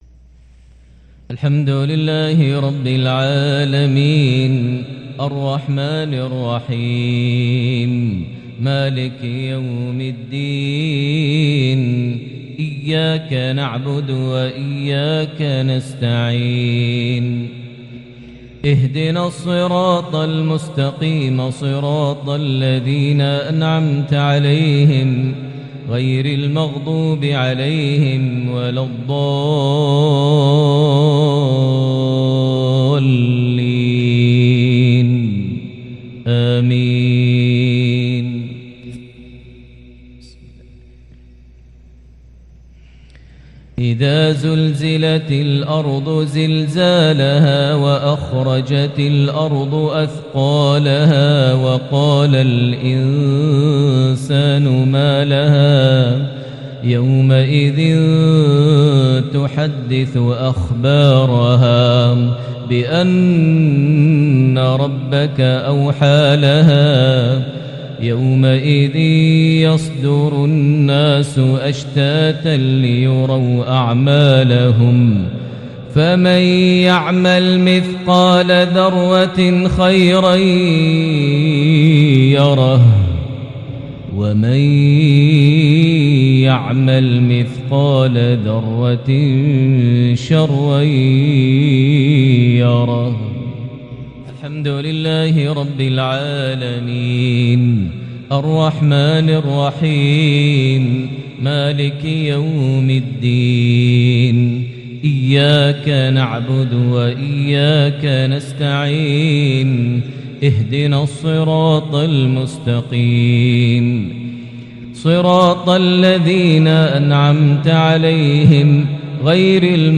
maghrib 5-3-2022 prayer from Surah Al-Zalzala + Surah Al-Qaria > 1443 H > Prayers - Maher Almuaiqly Recitations